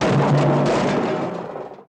Garbage Cans
Garbage Cans Tumble